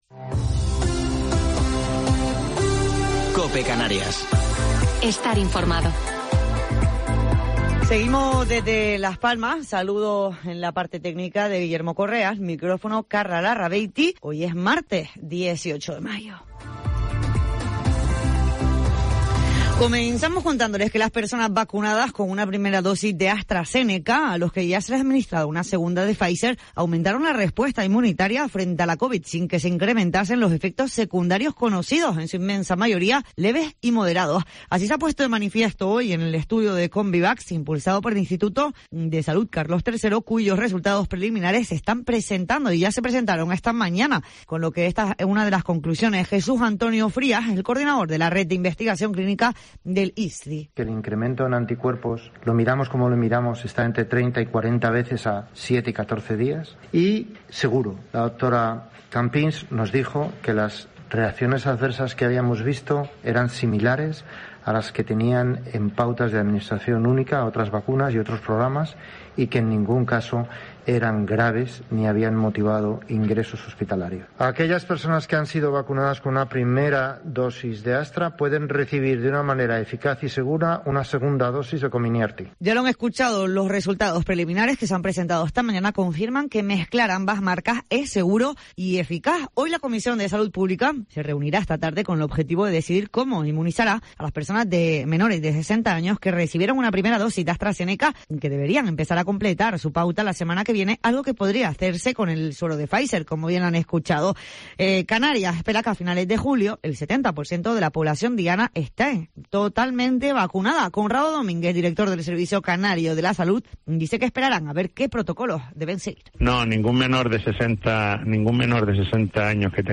Informativo local 18 de Mayo del 2021